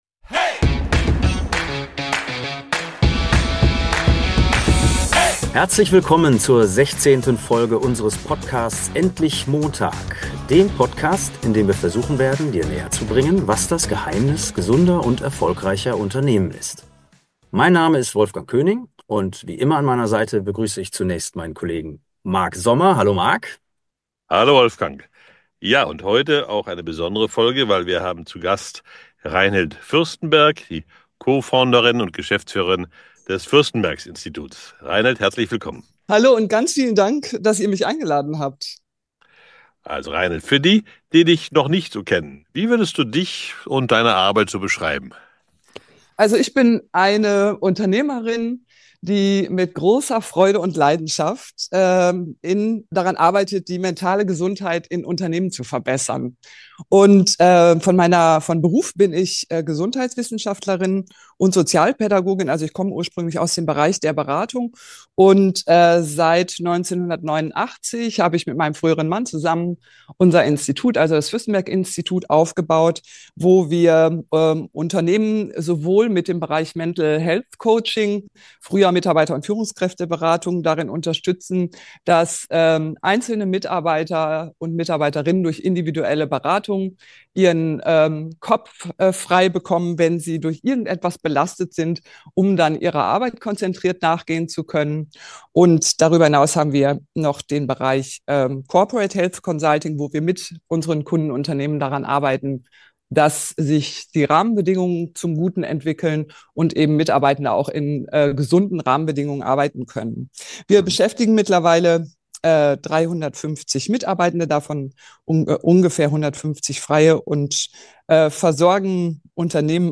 #016 Gesundheit ist ansteckend - ein Gespräch